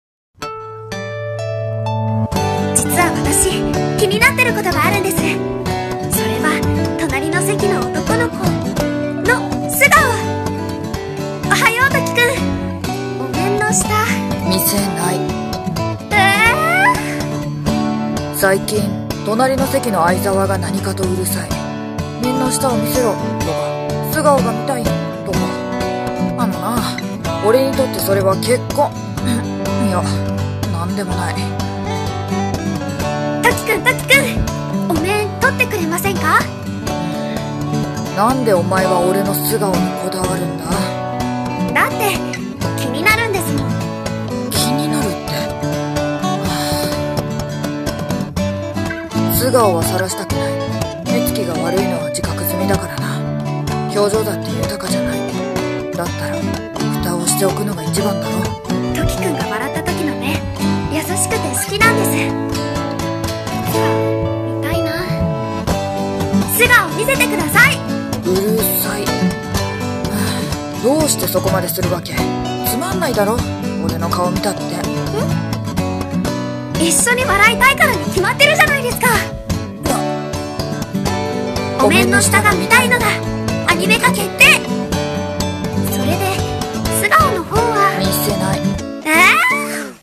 【CM風声劇】